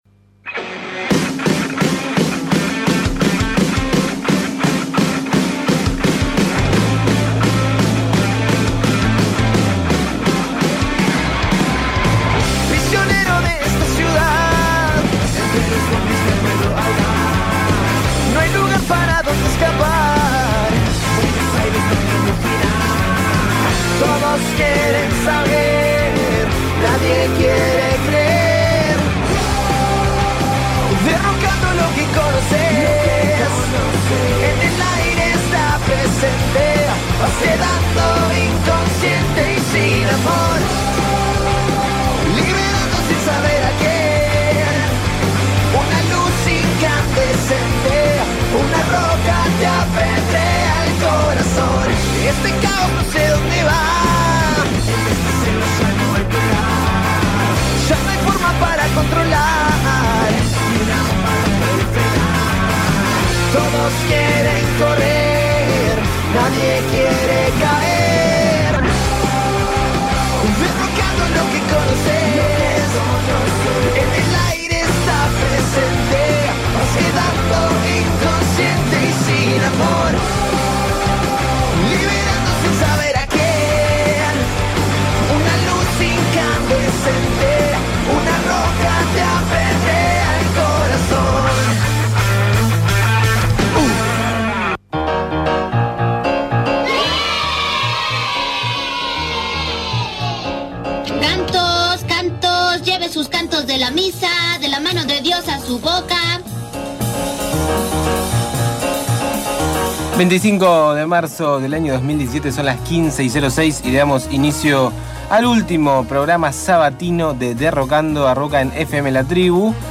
Todo concluye al fin... pero como nos sentimos muy bien cada sábado en el aire de LA TRIBU 88.7 FM nos teníamos que despedir de esta manera, con un programón: